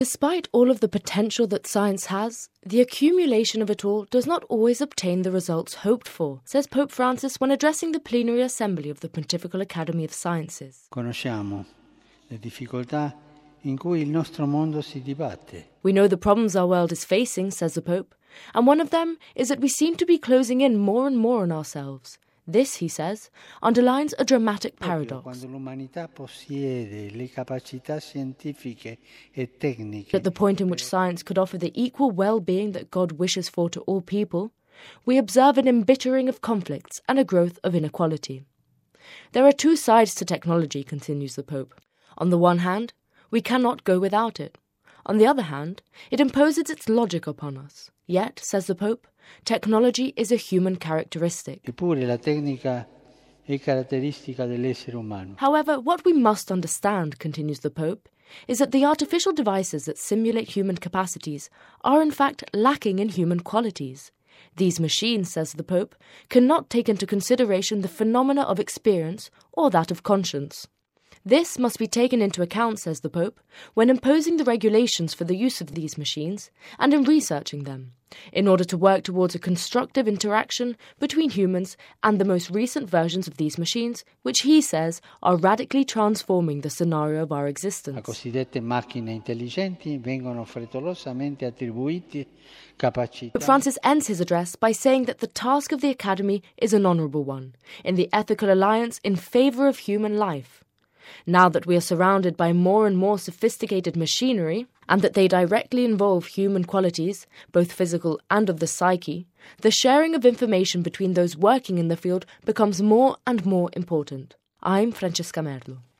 A new Seminarian